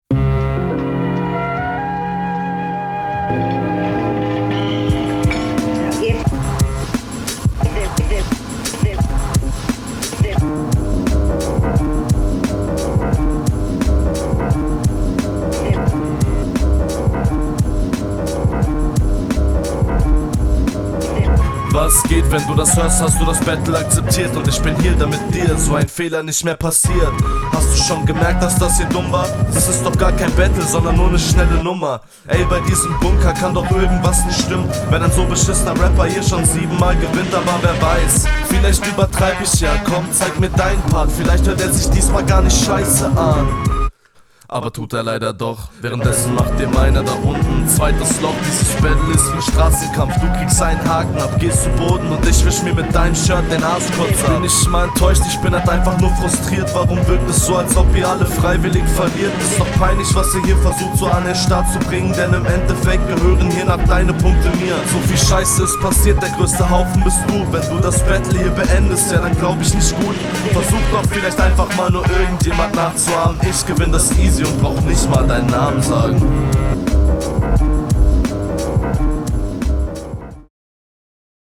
rap klingt schonmal sehr nice. lines wirken etwas nichtssagend. coole formulierungen und nice betont aber …
Grausamer Beat 😃 aber du nimmst den dafür doch ganz cool, Textlich cool mit viel …
Jo fangen wir mal an zu Bewerten, was gerrr Leuts, Beat ist übergeil, der subbase …